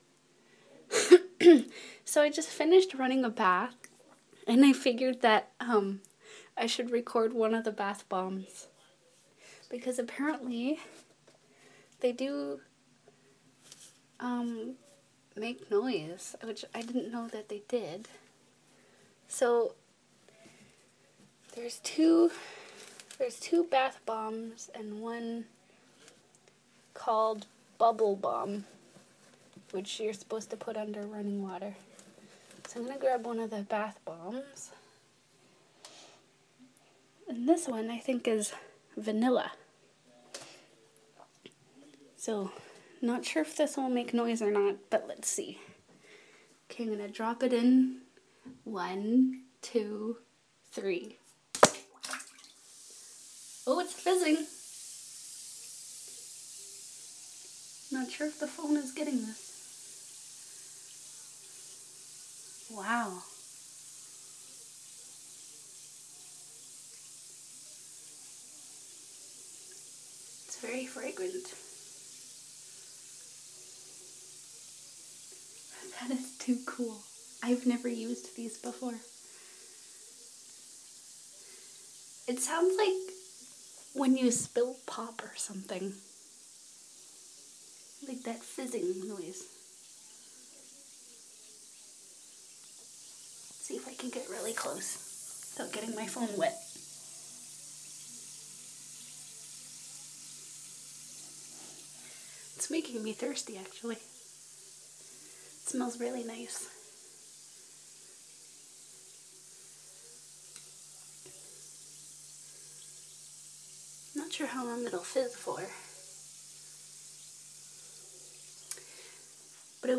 What does a bath bomb sound like